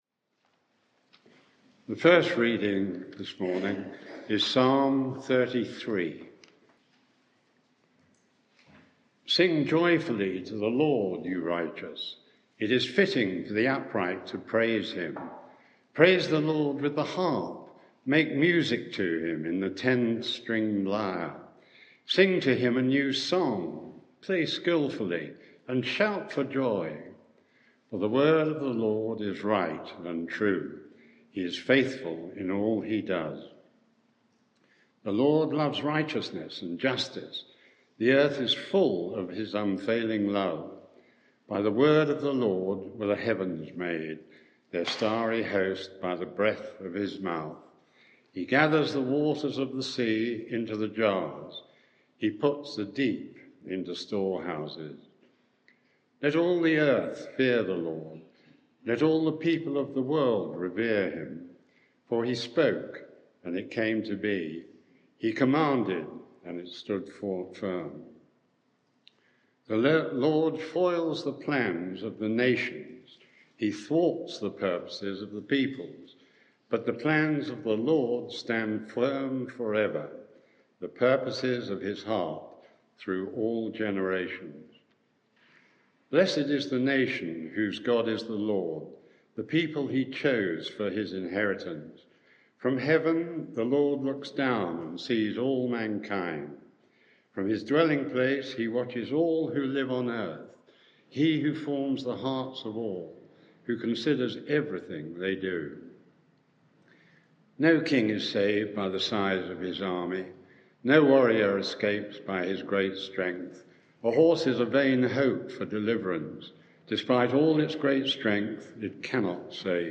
Media for 11am Service on Sun 10th Jul 2022 11:00 Speaker
Sermon (audio)